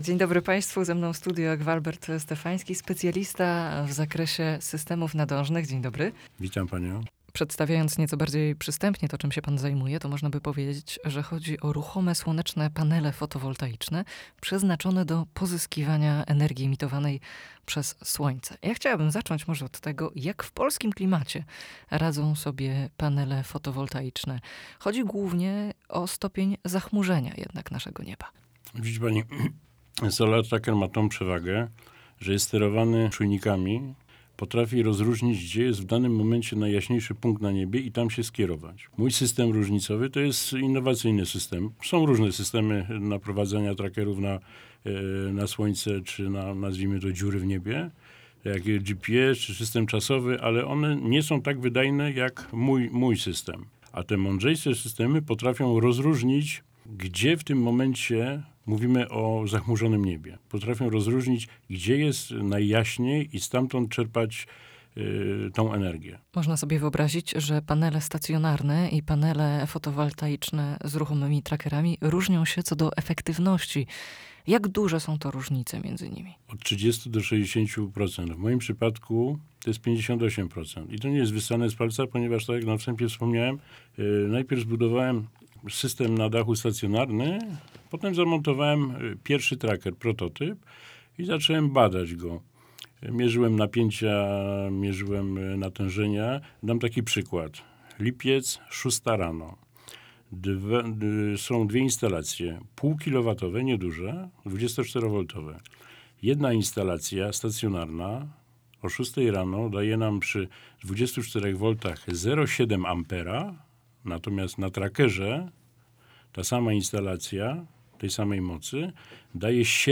Wywiad-w-radio-Krakow.wav